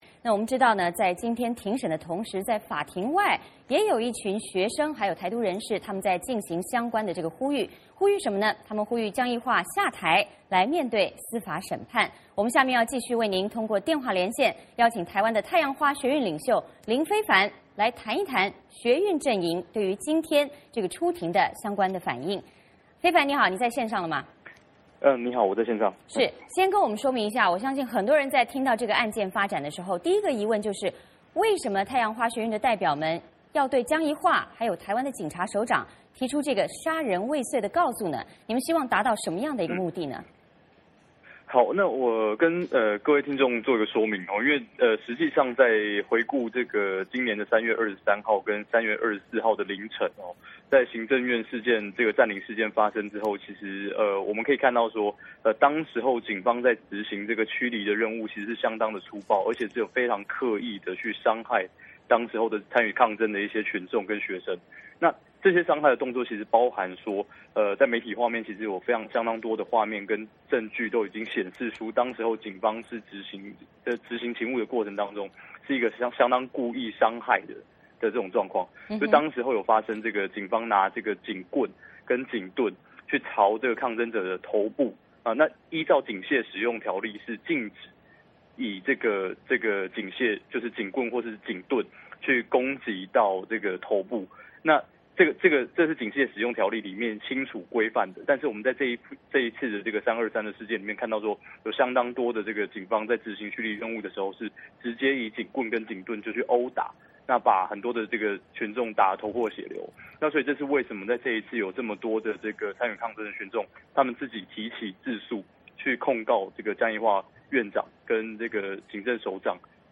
与此同时，包括台湾学运和台独人士聚集在法院外呼吁江宜桦下台，面对审判。下面我们继续通过电话连线，请台湾太阳花学运领袖林飞帆来谈谈他们的最新反应。